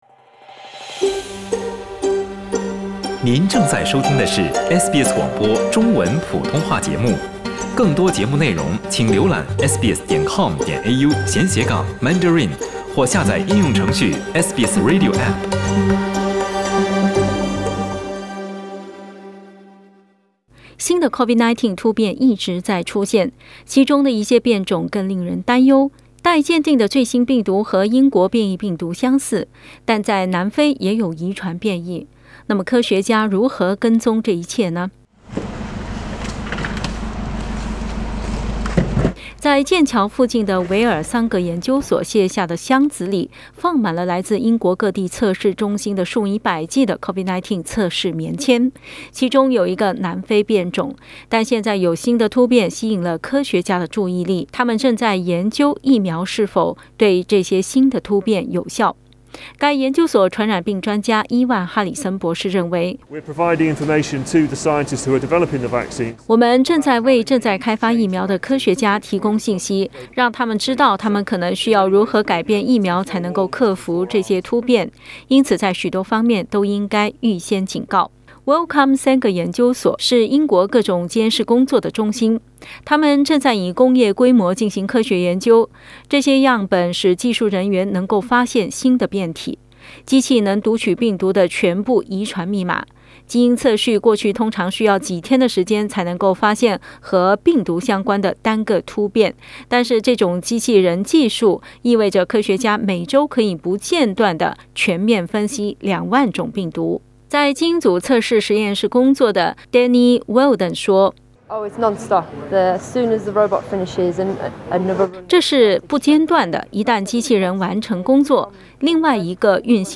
点击图片收听详细报道。